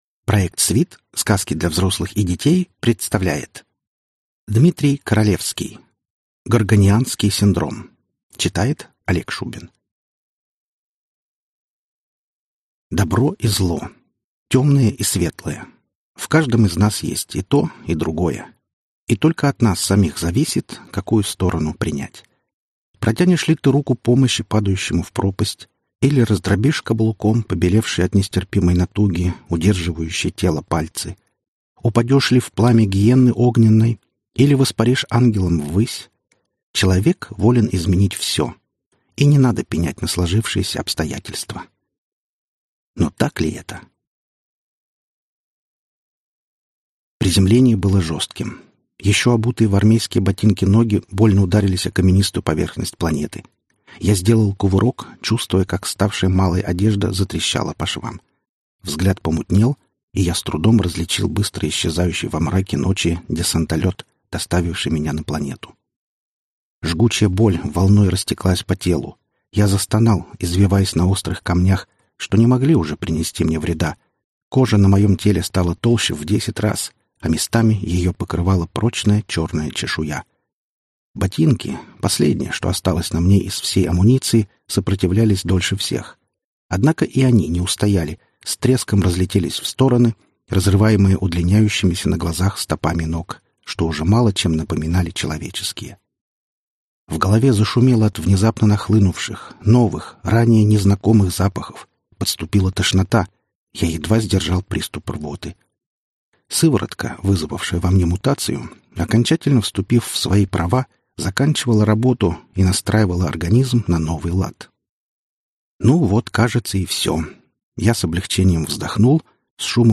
Аудиокнига Прыжки по фантастическим мирам | Библиотека аудиокниг